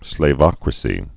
(slā-vŏkrə-sē)